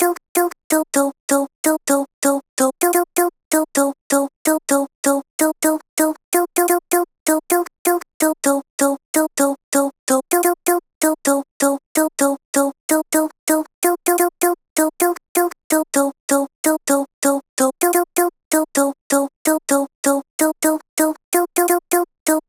NRG Lead2 3.wav